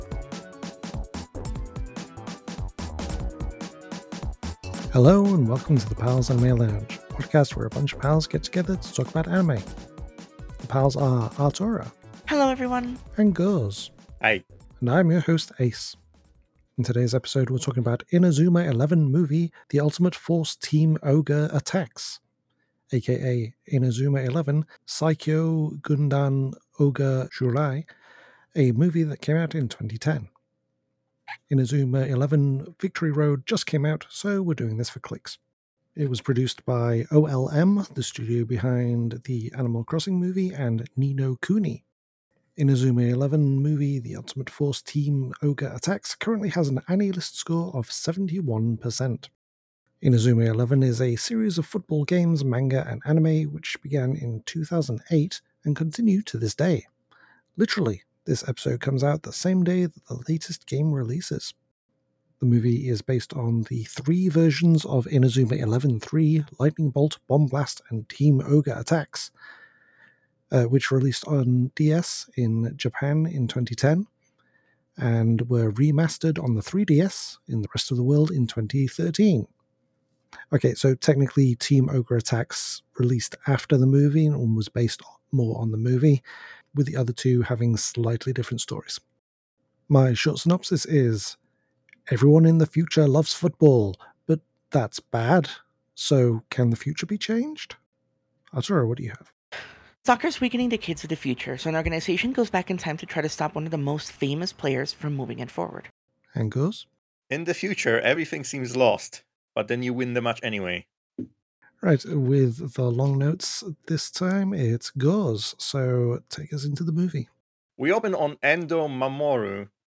Welcome to the Pals Anime Lounge, a podcast where a bunch of pals get together to talk about anime!